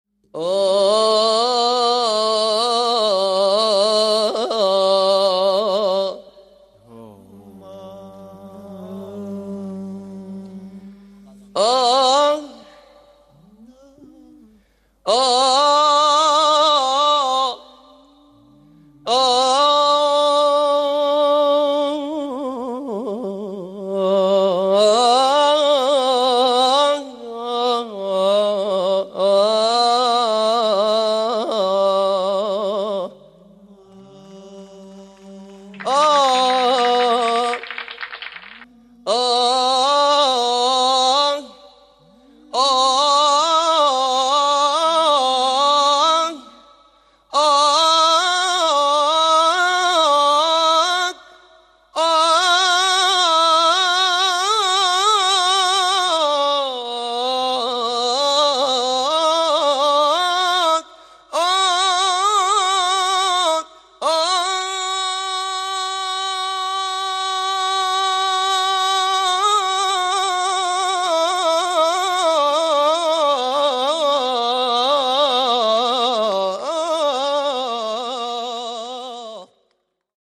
لمن هذه الآهات
صياح ياناس